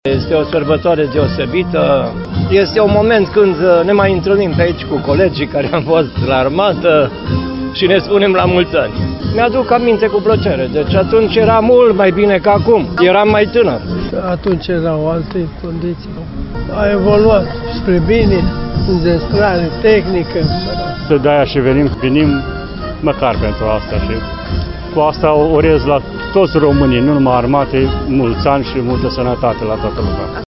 Voxuri-ziua-armatei.mp3